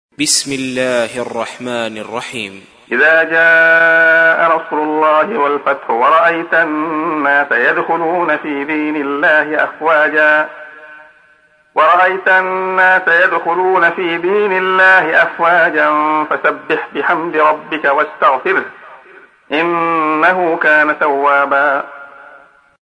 تحميل : 110. سورة النصر / القارئ عبد الله خياط / القرآن الكريم / موقع يا حسين